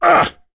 Kaulah-Vox_Damage_02.wav